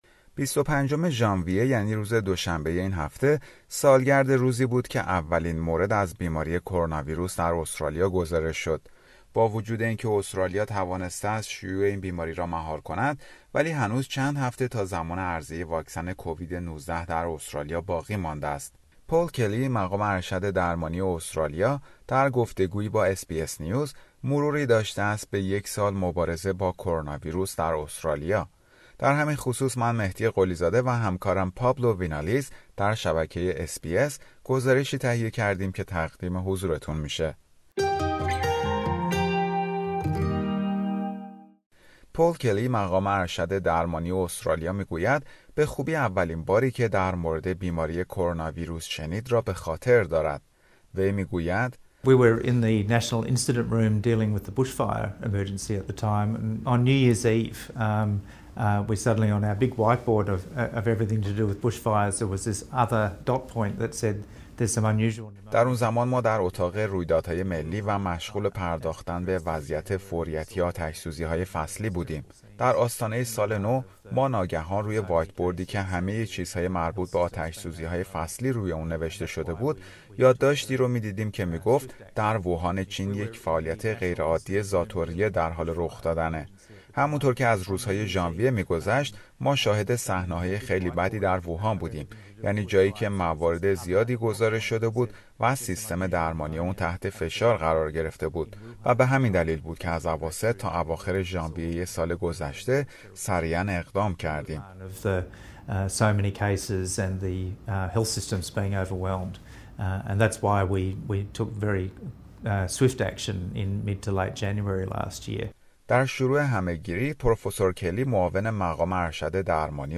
یک سال پس از شناسایی نخستین مورد ابتلا به کووید-۱۹ در استرالیا؛ گفتگو با عالی رتبه ترین مقام پزشکی استرالیا